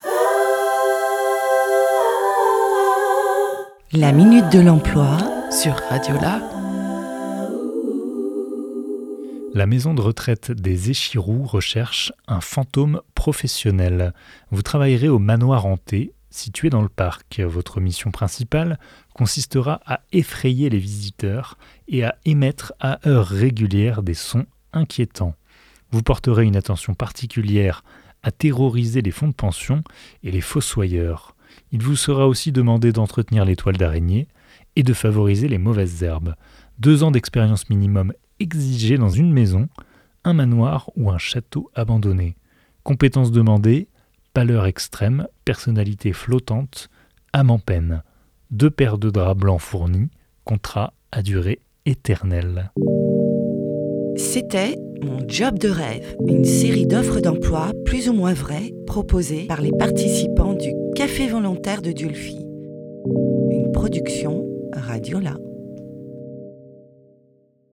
« Mon job de rêve » est une série d’offres d’emploi décalées, écrite et enregistrée par des participant-es du Café volontaire de Dieulefit en juillet 2024.